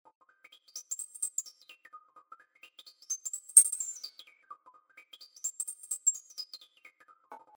■　2×2 Pole ローパス・フィルターのカットオフをオートメーションさせたハイハット・トラック。Inertiaは100%に設定。
Inertia_high.mp3